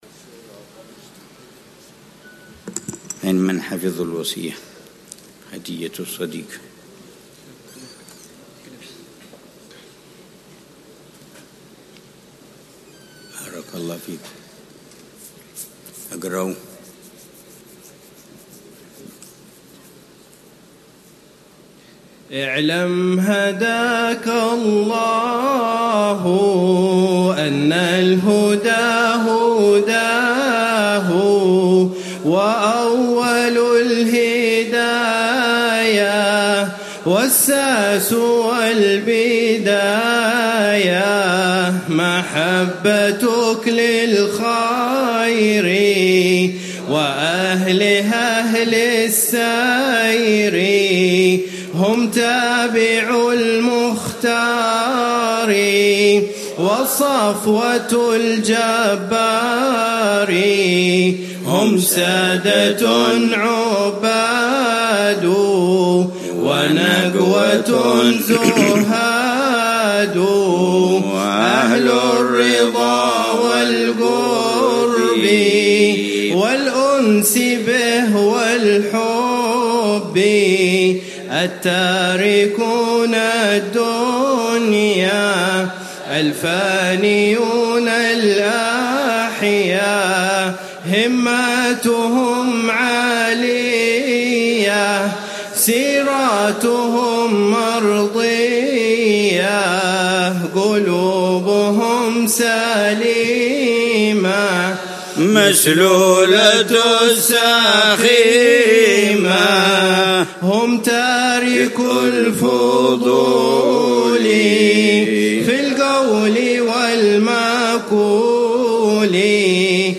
الدرس العاشر (21 محرم 1447هـ)